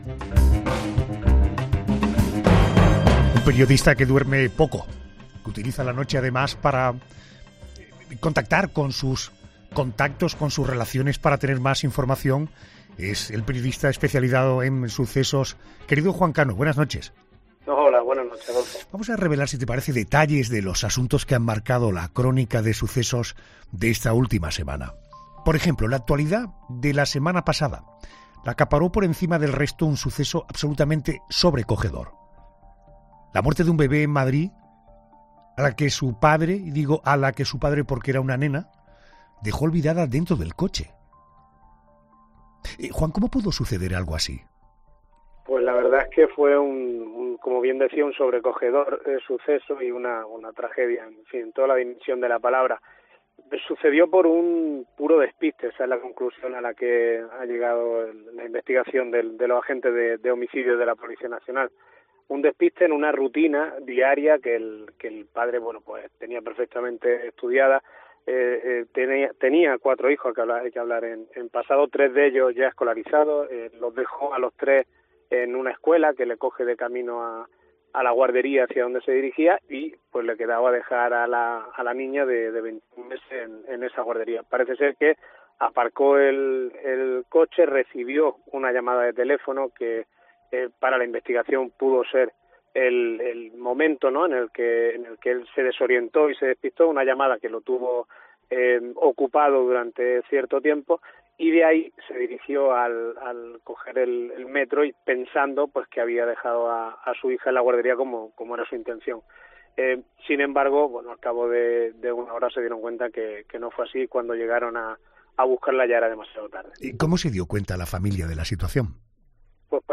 Repaso a la crónica de sucesos con el periodista